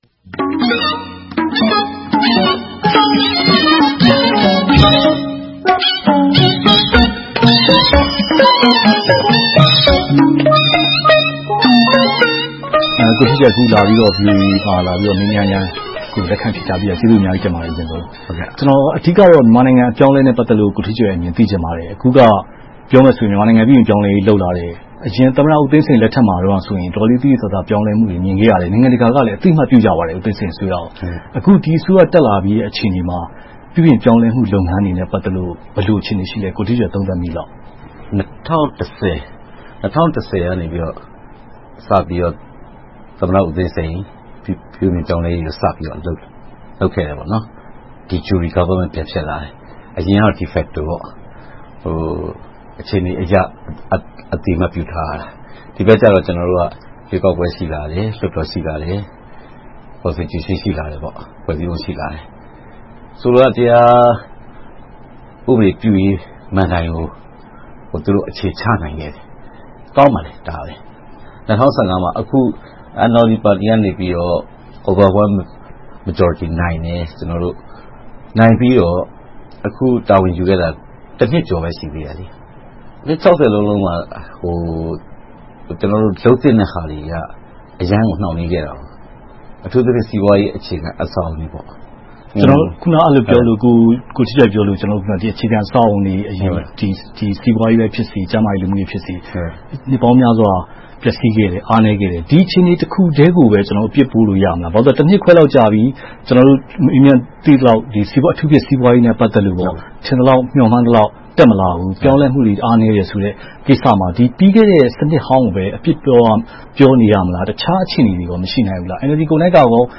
ဝါရှင်တန်ဒီစီ VOA Studio မှာ